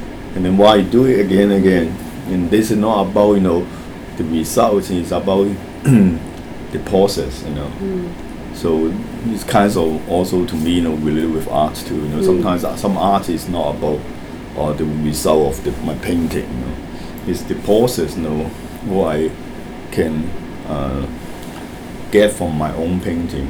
S1 = Taiwanese female S2 = Hong Kong male
Intended Words : process , related with Heard as : causes , a believer of Discussion : The main problem with process is the absence of [r] . Perhaps the main problem with related with is the speed it is spoken, so that the third syllable of related is omitted, and the vowel in the second syllable is [i] rather than [eɪ] . The phrase is pronounced as [riliwɪv] .